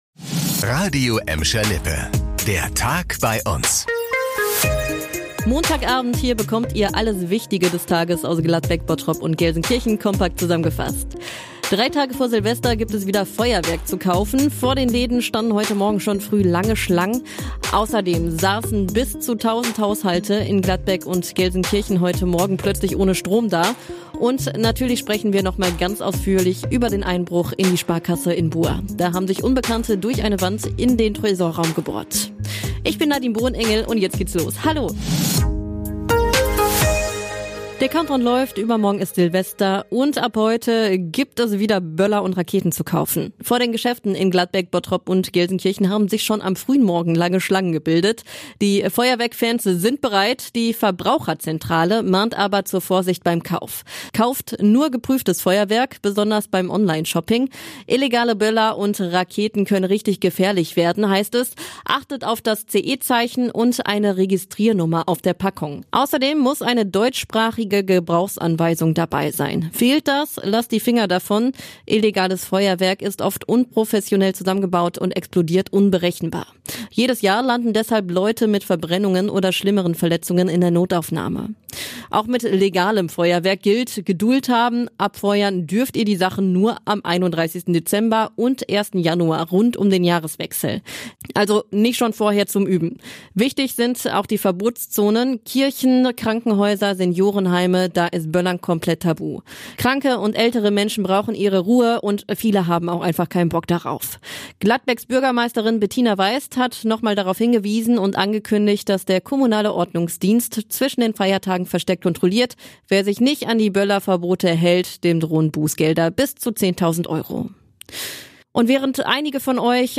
Be a guest on this podcast Language: de Genres: Daily News , News Contact email: Get it Feed URL: Get it iTunes ID: Get it Get all podcast data Listen Now...